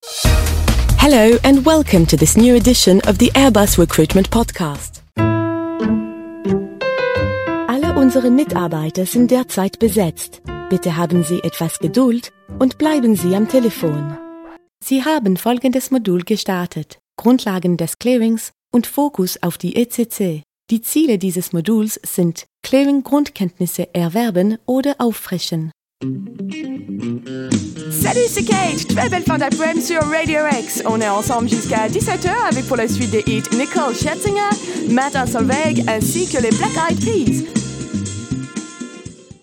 Sprechprobe: Sonstiges (Muttersprache):
Home studio, prompt delivery.